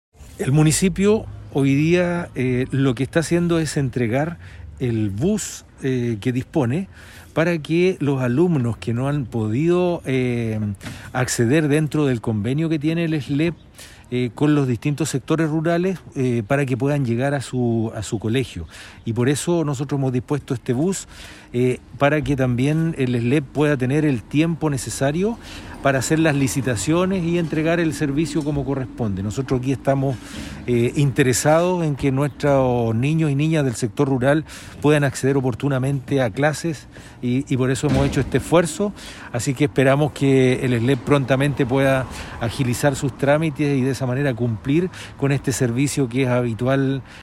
CUNA-1-ALCALDE-ARMANDO-FLORES-.mp3